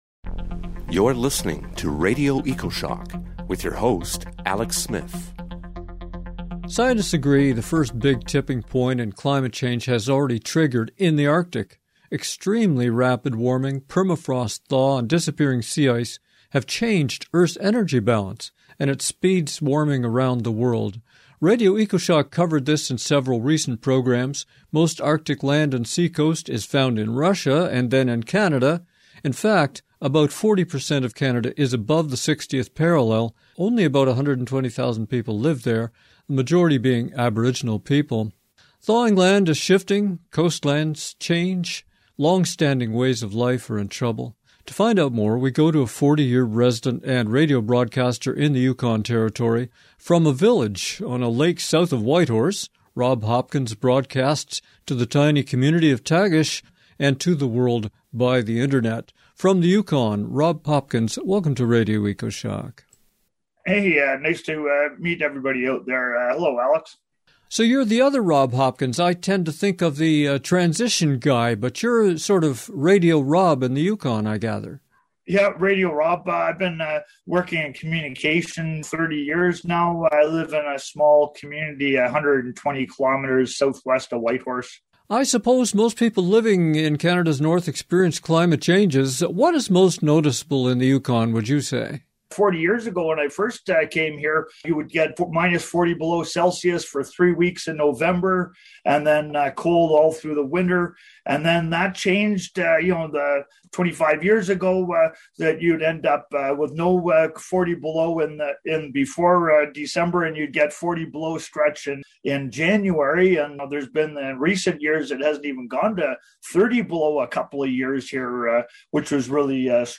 Direct from the Yukon